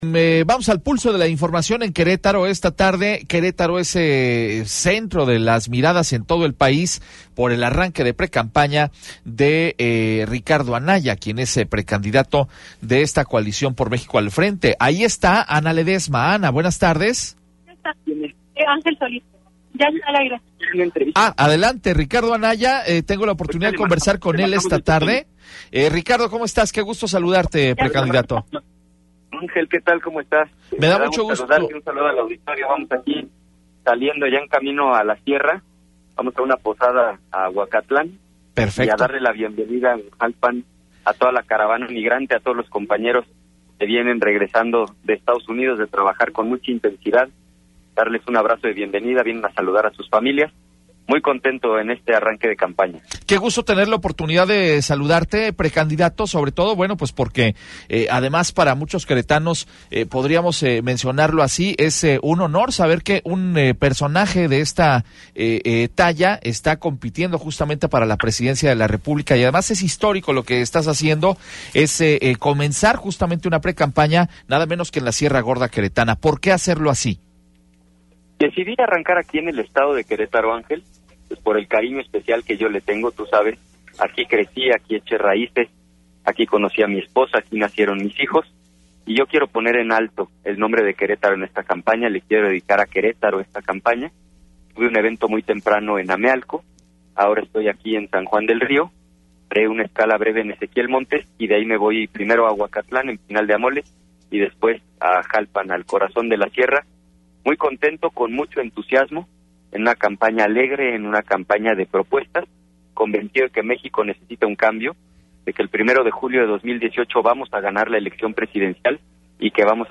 Entrevista exclusiva con Ricardo Anaya precandidato de la Coalición «Por México al Frente»
ENTREVISTA-ANAYA-.mp3